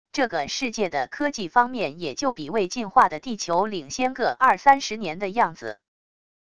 这个世界的科技方面也就比未进化的地球领先个二三十年的样子wav音频生成系统WAV Audio Player